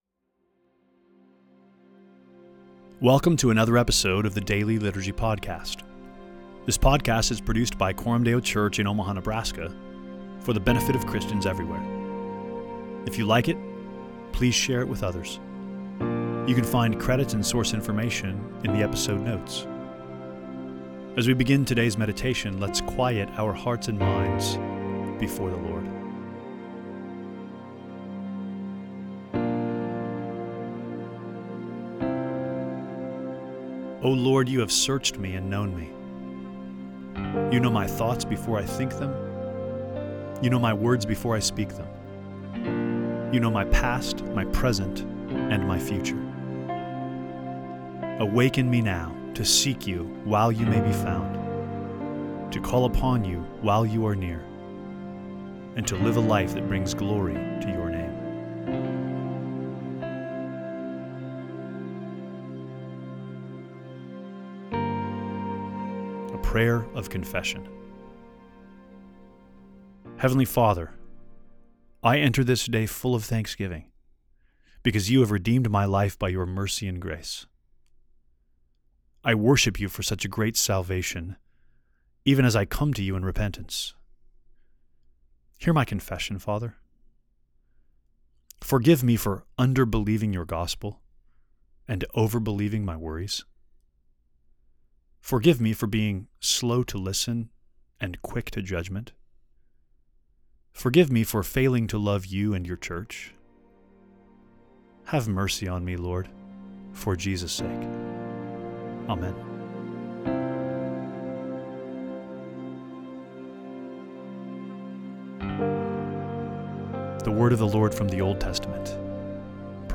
The Daily Liturgy Podcast provides Christians everywhere with a historically informed, biblically rich daily devotional in audio format.